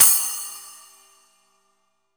08SPLASH.wav